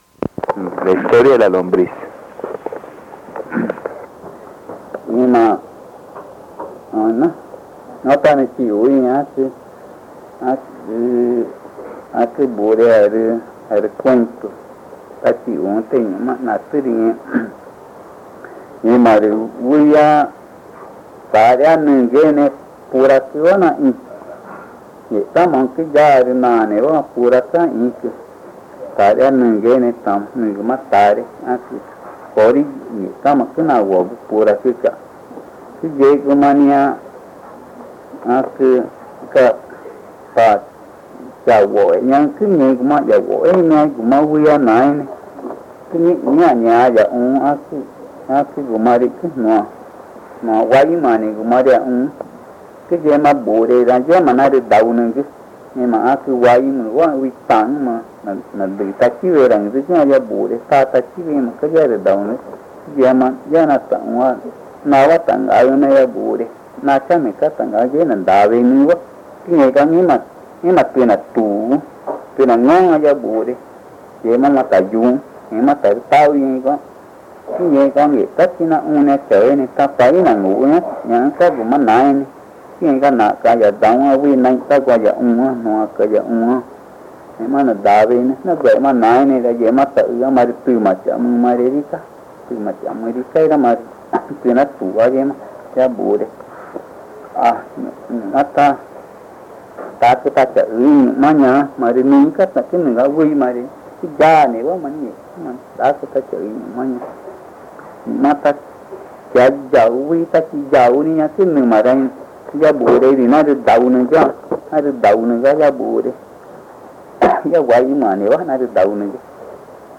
Río Amazonas (Colombia), problamente Pozo Redondo